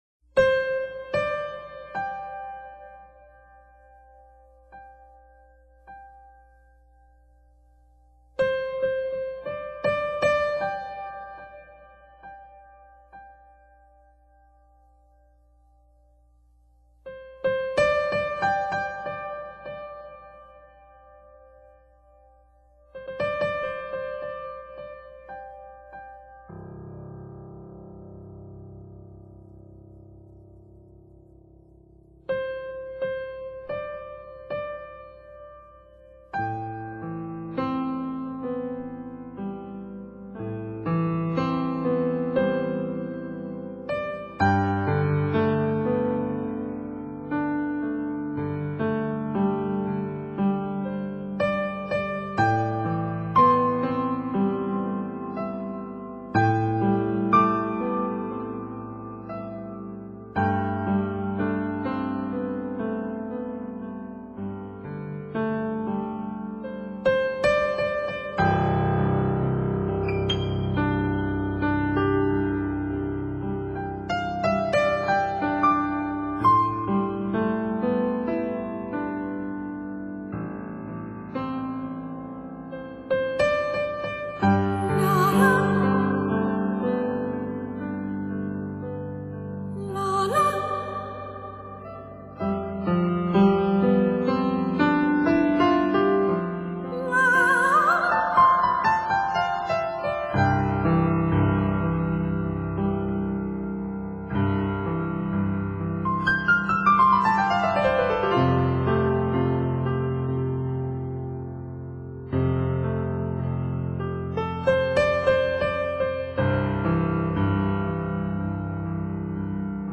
器乐演奏家系列
钢琴